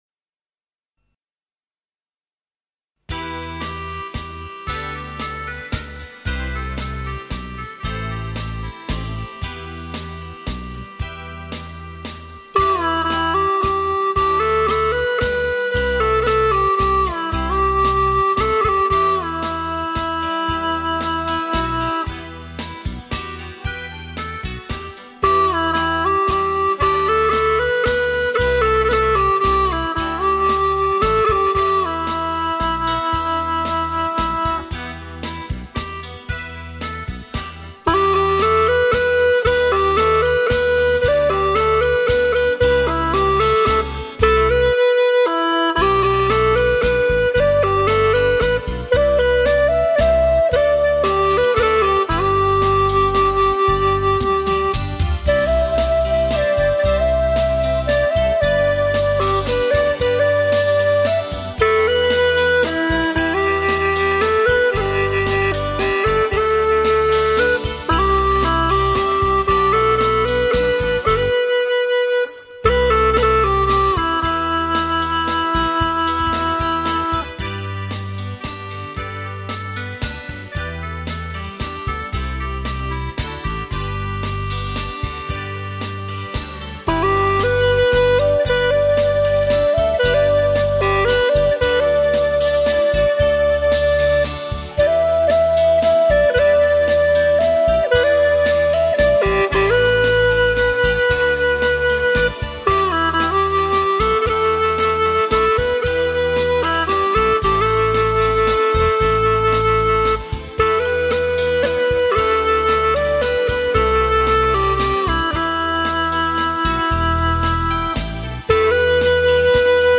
顶级HI-FI黑胶天碟 再创黑胶唱片神话 极致声音共谱唯美。
葫蘆絲